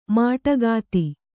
♪ māṭagāti